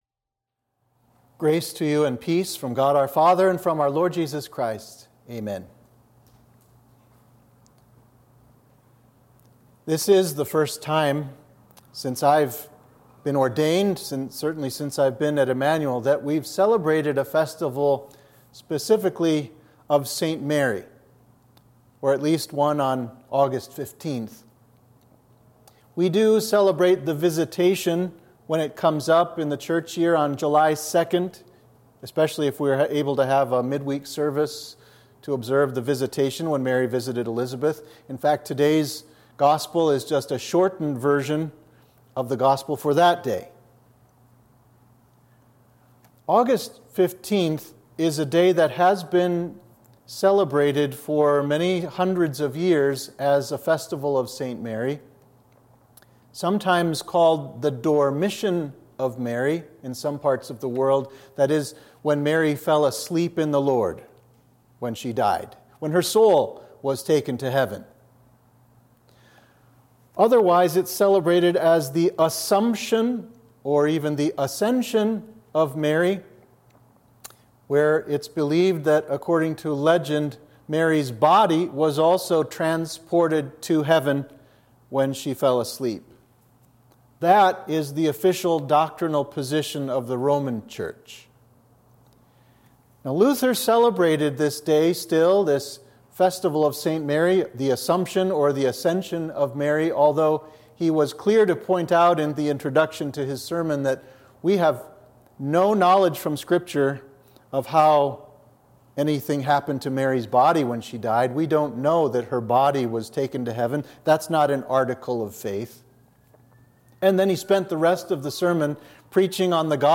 Sermon for the Festival of St. Mary, Theotokos